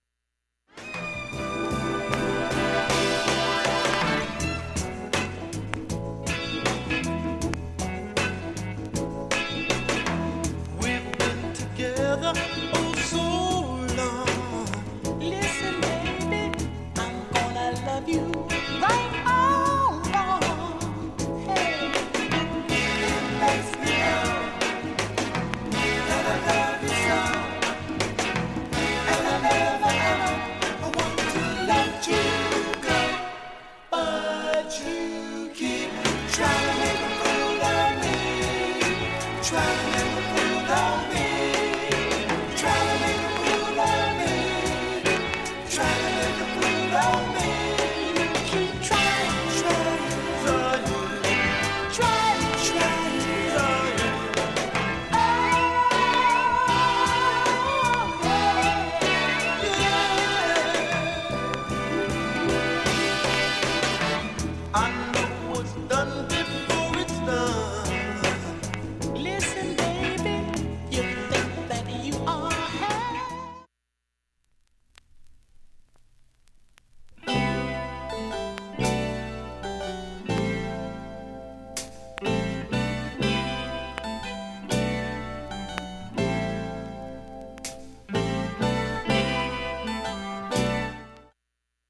音質良好全曲試聴済み。
周回プツ出ますがかすかです。
2,(1m14s〜)B-3始めにかすか8回プツ
音質目安にどうぞ
５回までのかすかなプツが2箇所
３回までのかすかなプツが3箇所
単発のかすかなプツが7箇所